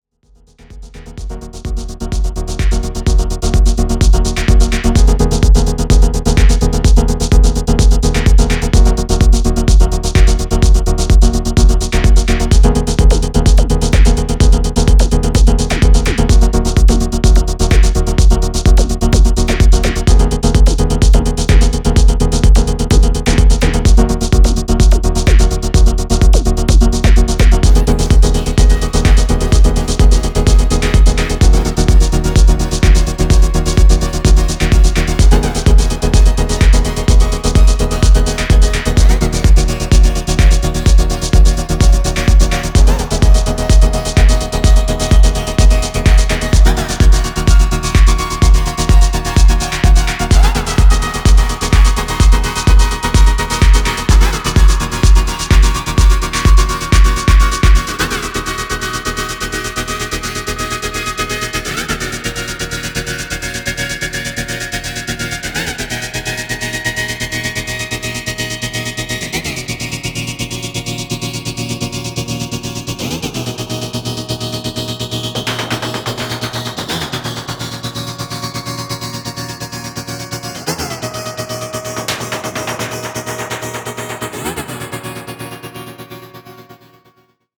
audio remasterizado